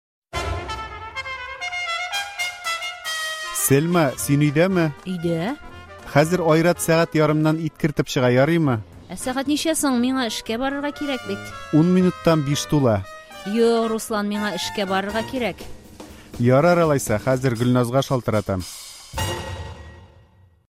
ДИАЛОГ “Миңа эшкә барырга кирәк”